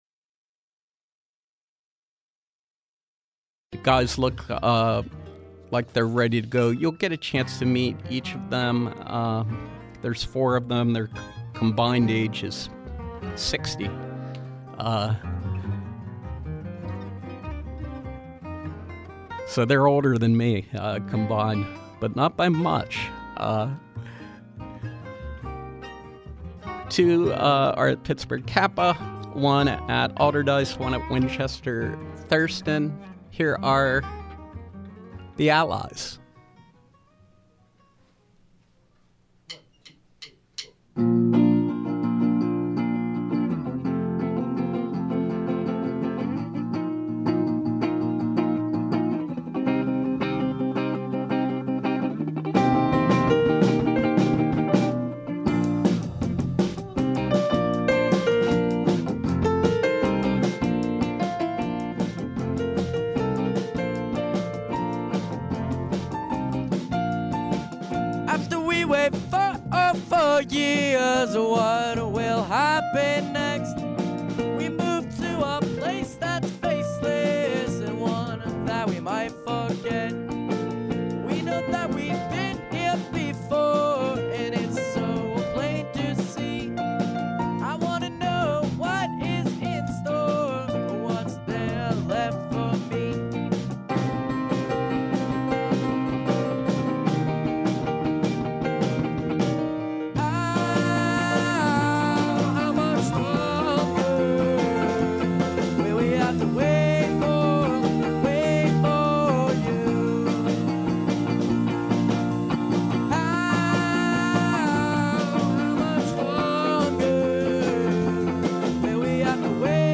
From 10/05/2019 – Live music with youth indie-rock band The Allies